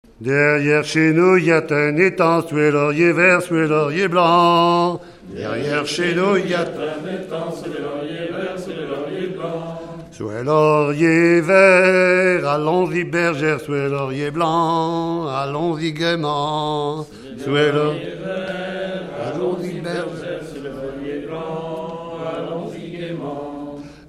Genre laisse
enregistrement de chansons
Pièce musicale inédite